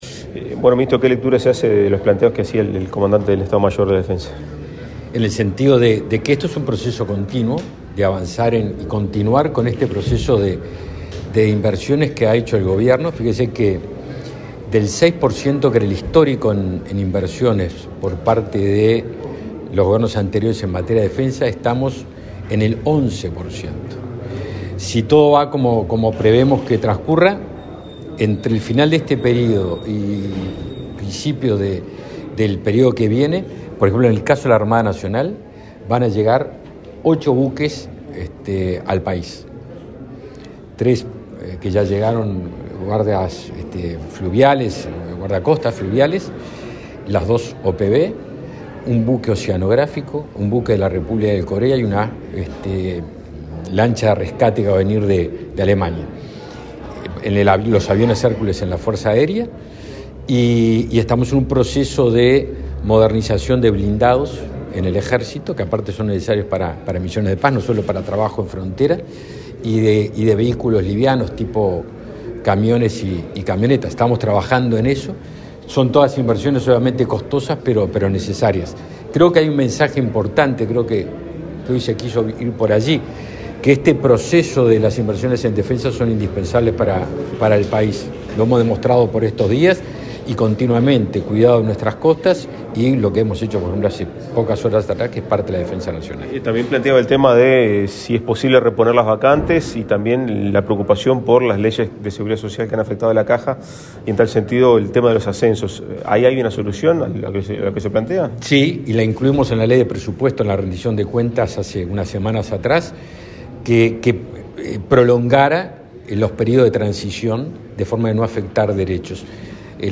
Declaraciones a la prensa del ministro de Defensa Nacional, Javier García
Tras el evento, el ministro de Defensa Nacional, Javier García, realizó declaraciones a la prensa.